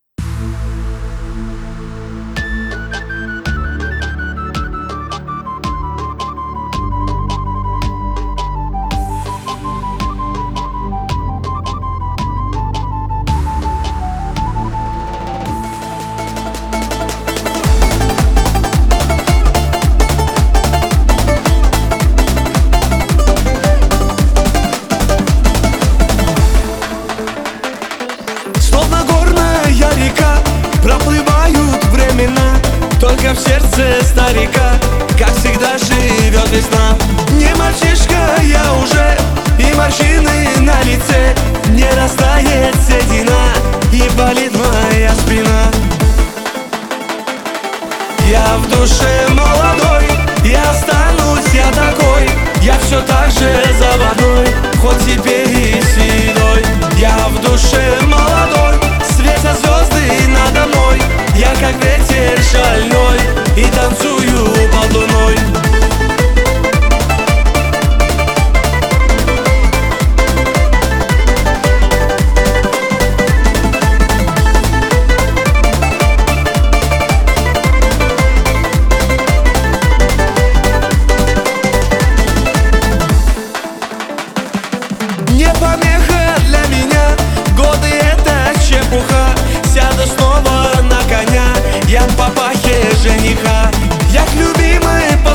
это яркая и энергичная песня в жанре поп с элементами фолка